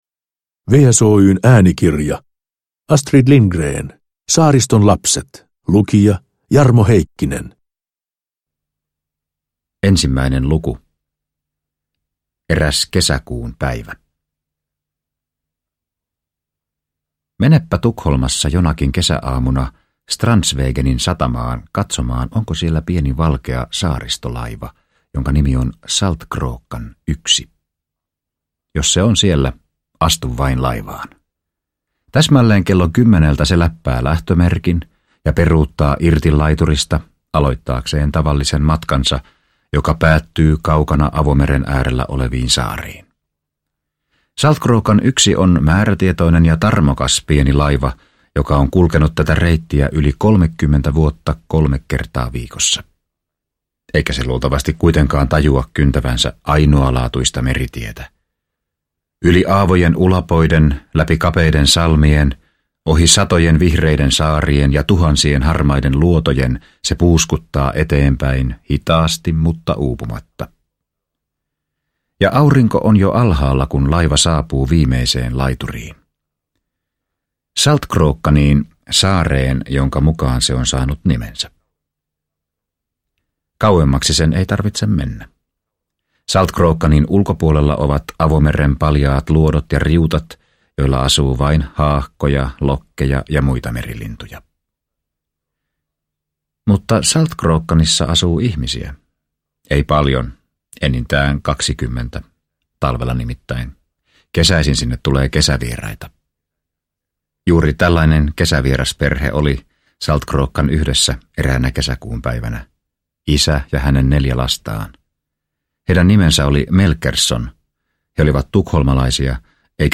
Saariston lapset – Ljudbok – Laddas ner